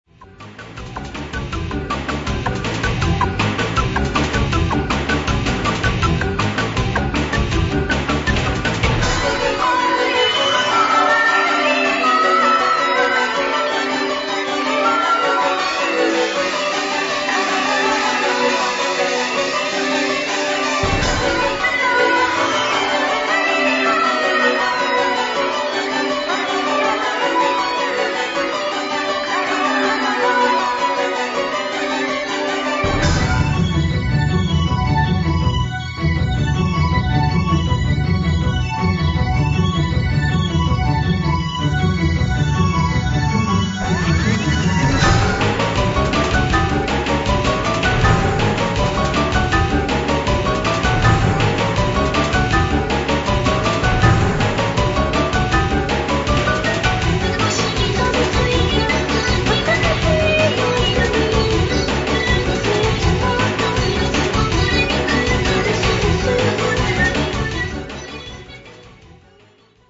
電脳空間を浮遊する女声Voが物語る博物誌